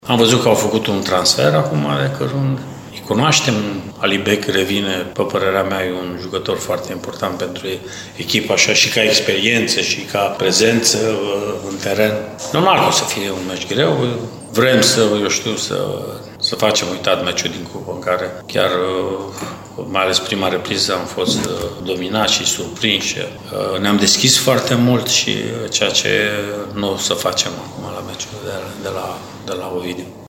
Înaintea plecării spre Litoral, antrenorul Mircea Rednic a vorbit și despre adversară: